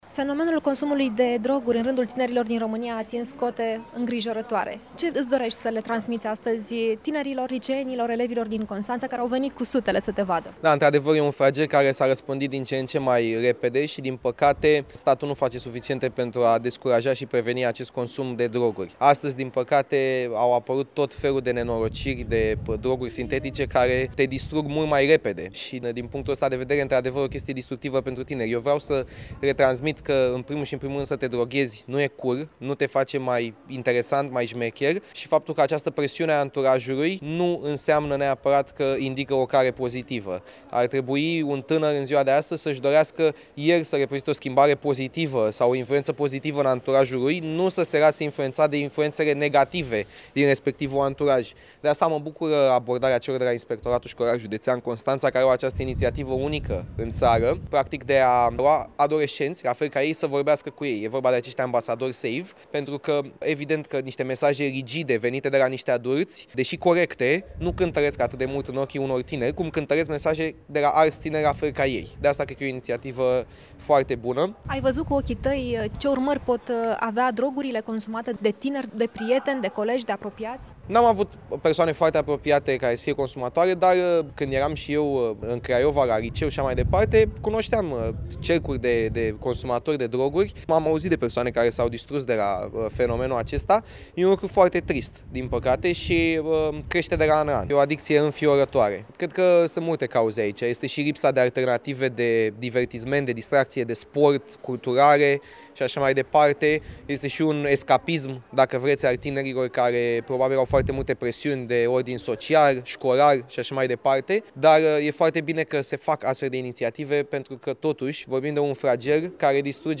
Iată mesajul pe care influencerul Andrei Șelaru (alias Selly) l-a transmis celor peste 600 de liceeni din Constanța invitați să îl asculte în cadrul conferinței intitulate „Alternative – să fii cool”.
Evenimentul s-a desfășurat la Centrul Multifuncțional Educativ pentru Tineret „Jean Constantin” în cadrul proiectului SAVE al Inspectoratului Școlar Județean Constanța, o inițiativă unică la nivel național.